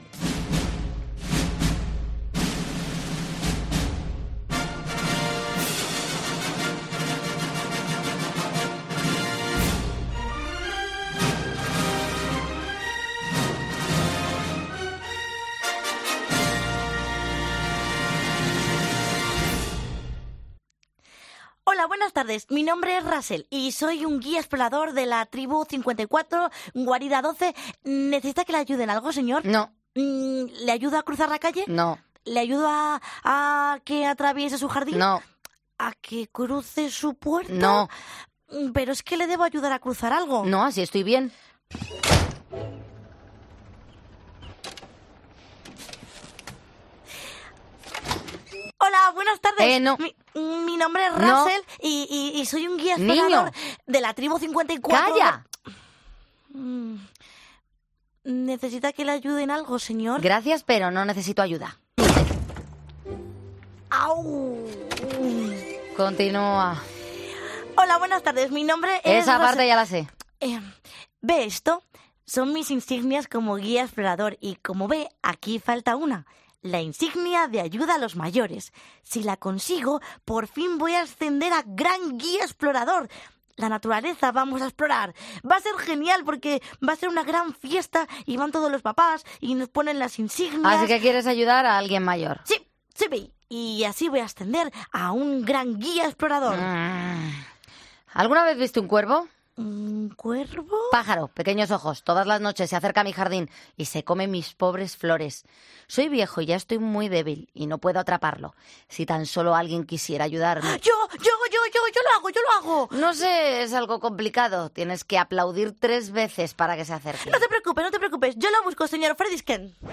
Como cada viernes, en Poniendo Las Calles, nos hacemos pasar por actores de doblaje y sale lo que sale... nosotros lo intentamos.